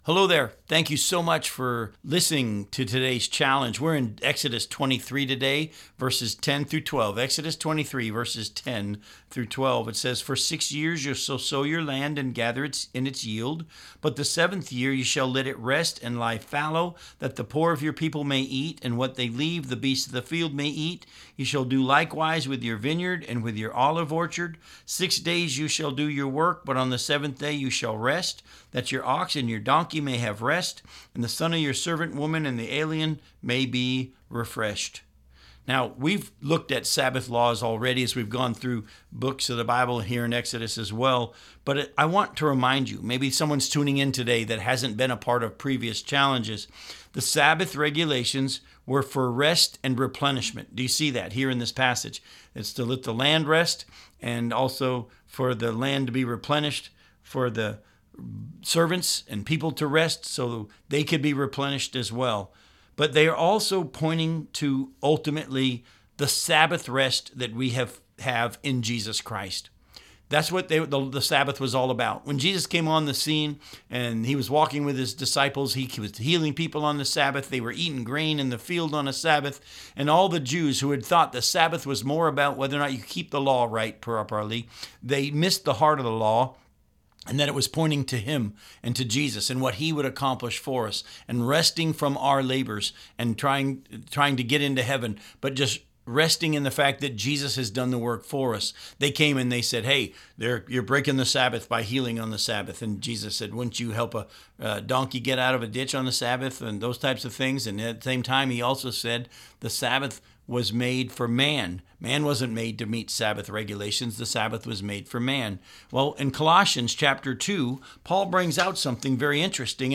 five-minute weekday radio program aired on WCIF 106.3 FM in Melbourne, Florida